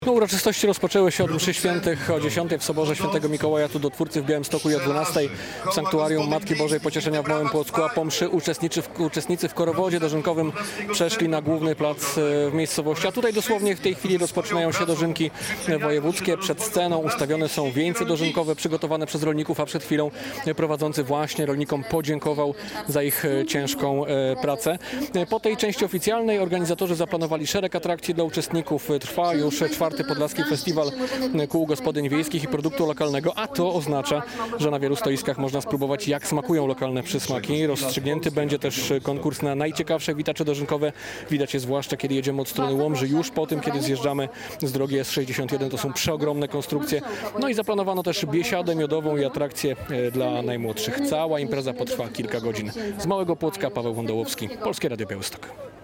Rozpoczynają się Dożynki Wojewódzkie w Małym Płocku - relacja